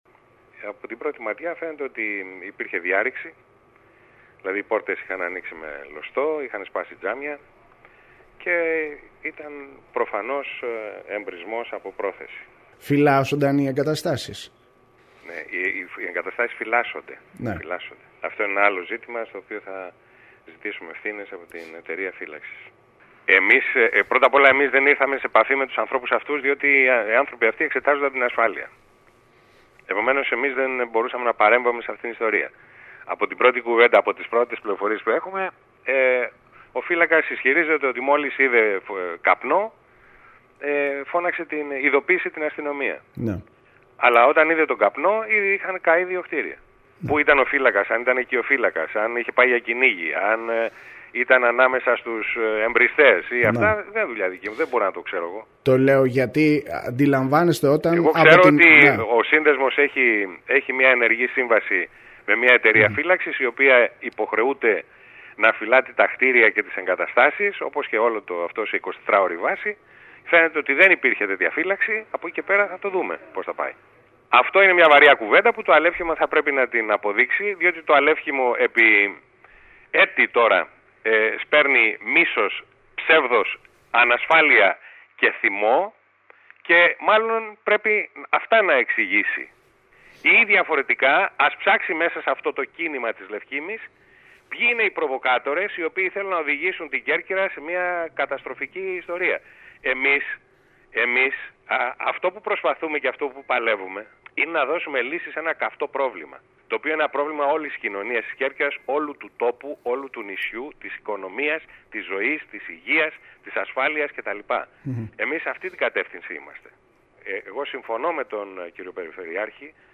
Ακούστε απόσπασμα των δηλώσεων του κ. Κ.Νικολούζου στον σύνδεσμο που ακολουθεί: